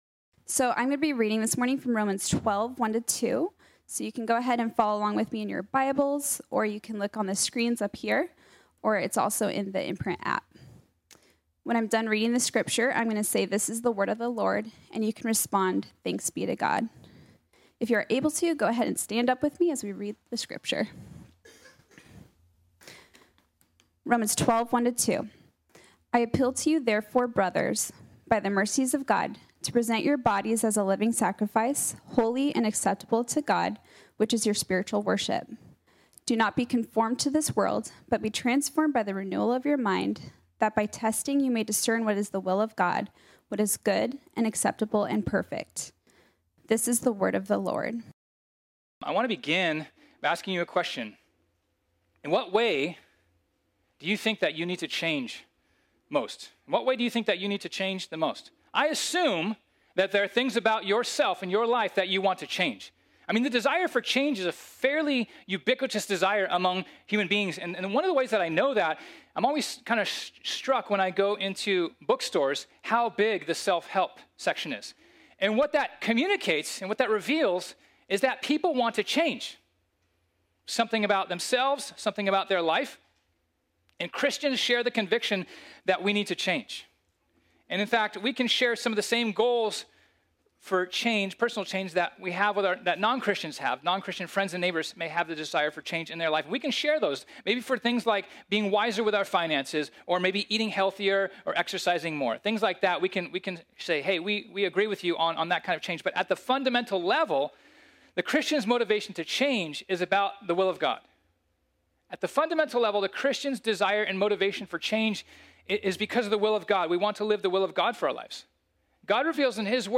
This sermon was originally preached on Sunday, June 27, 2021.